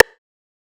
BOOMIN RIMSHOT HARD.wav